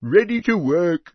PeasantReady1.mp3